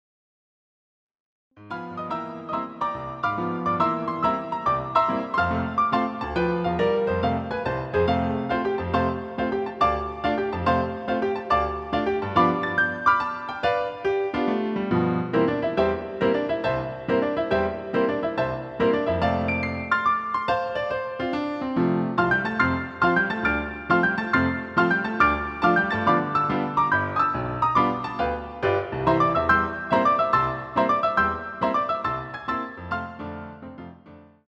CD quality digital audio Mp3 file